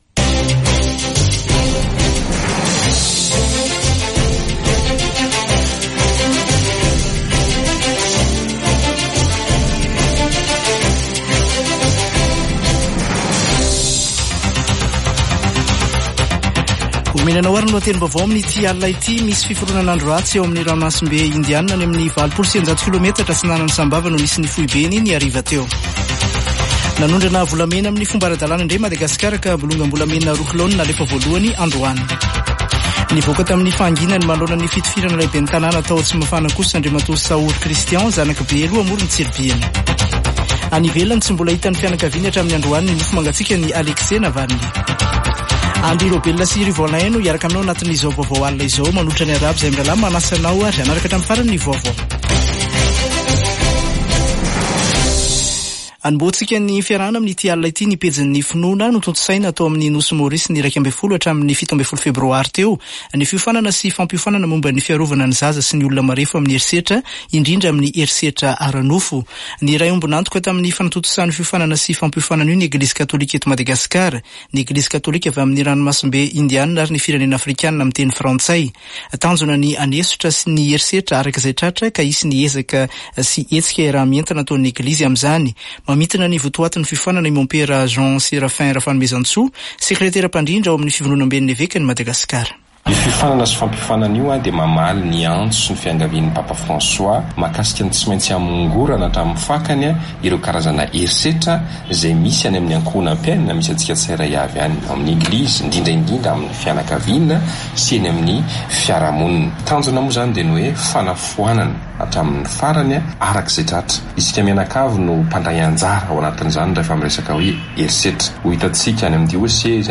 [Vaovao hariva] Alatsinainy 19 febroary 2024